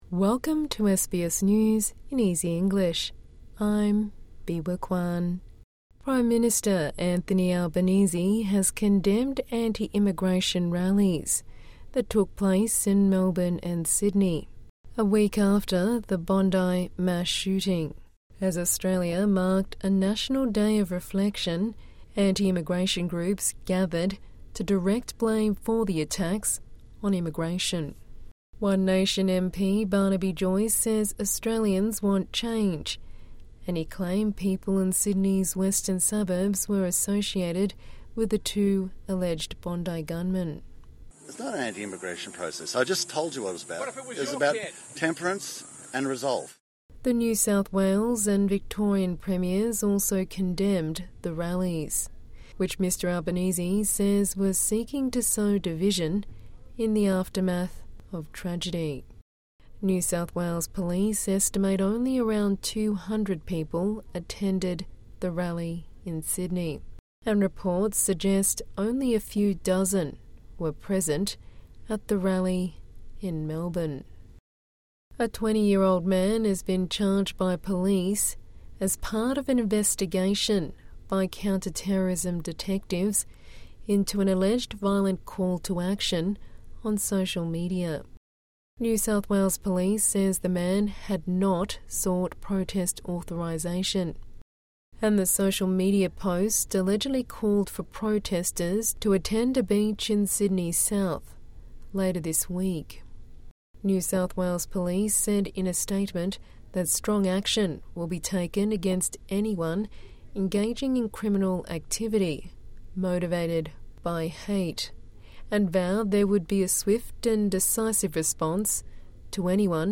A daily 5-minute news wrap for English learners and people with disability.